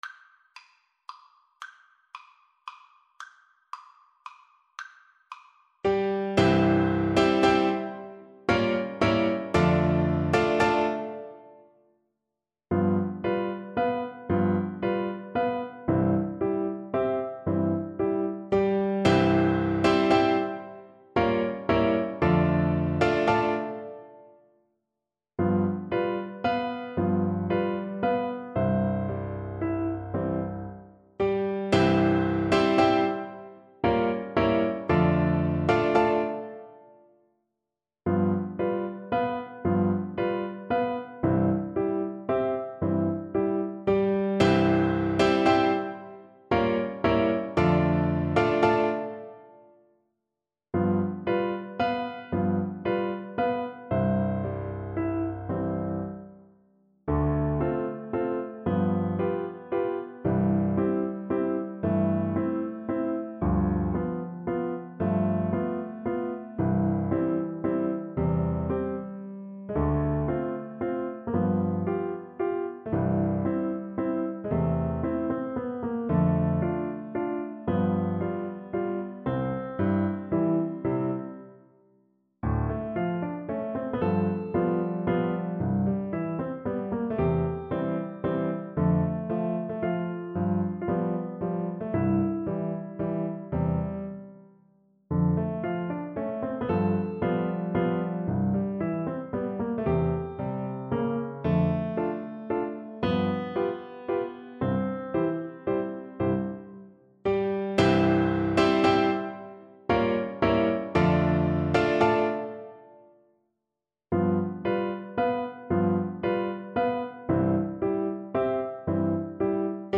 3/4 (View more 3/4 Music)
G4-C7
Allegro =160 (View more music marked Allegro)
Classical (View more Classical Violin Music)